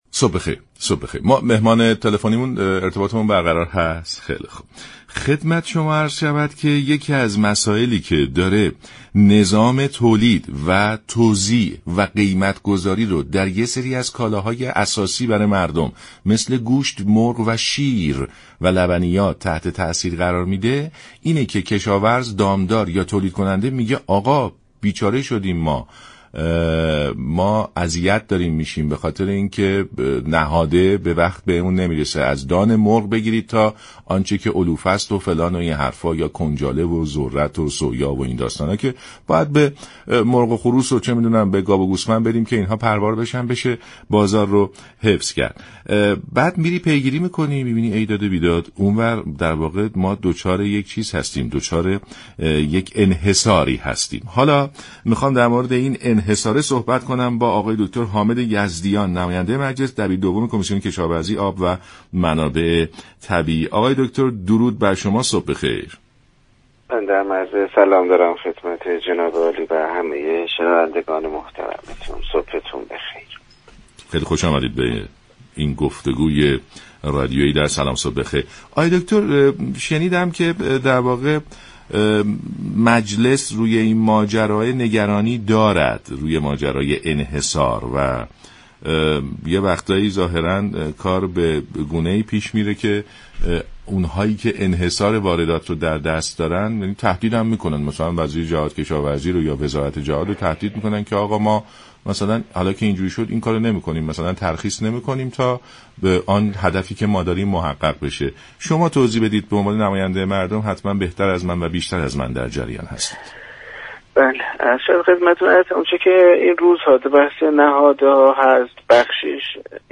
دبیر دوم كمیسیون منابع‌طبیعی مجلس در برنامه سلام‌صبح‌بخیر گفت: در طول سالیان اخیر انحصار واردات نهاده‌ها در دست دو شركت شناخته‌شده بوده است.